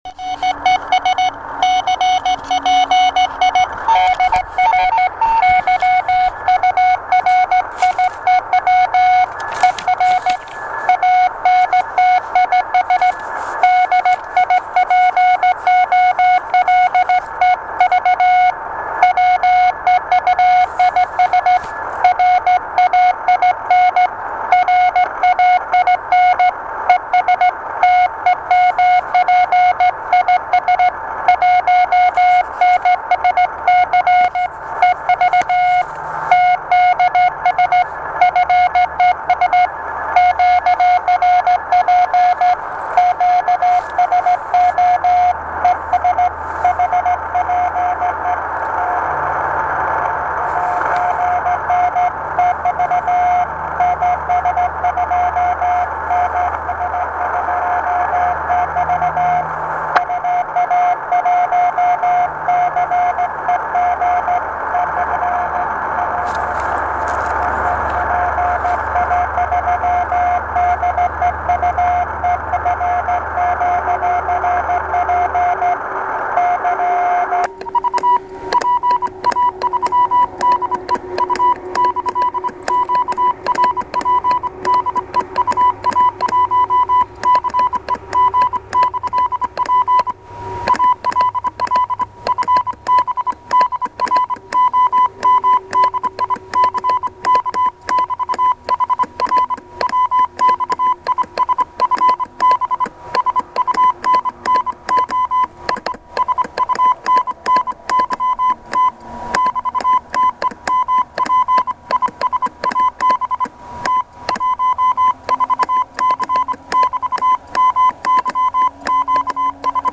ТЕМА: QSO на самоделках
Выходил в поля- леса поработать честными 5-ю ваттами в тесте "Kulikovo Polye Contest".